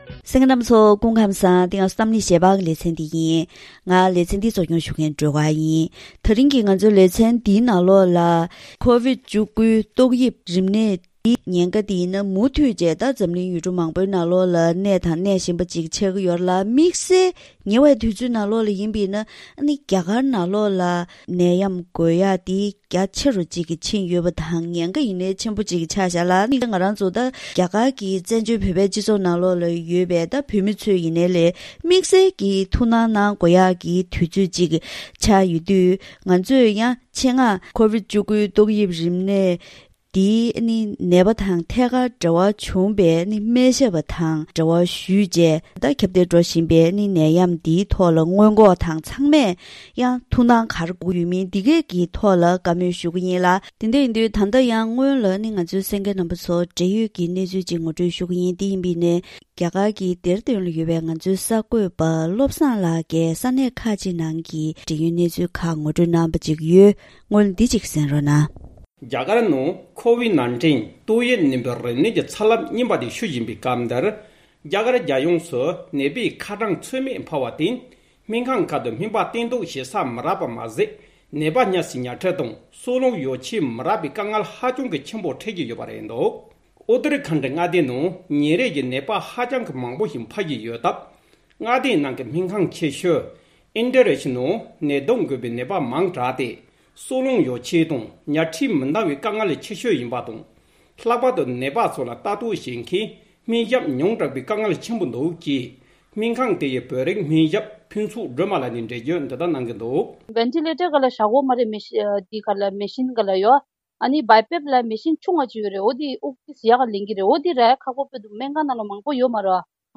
༄༅།།དེ་རིང་གི་གཏམ་གླེང་ཞལ་པར་ལེ་ཚན་ནང་ཀོ་ཝིཌ་༡༩ ཏོག་དབྱིབས་རིམས་ནད་འདི་བཞིན་རྒྱ་གར་ནང་ཁྱབ་གདལ་ཤུགས་ཆེན་འགྲོ་བཞིན་ཡོད་པས།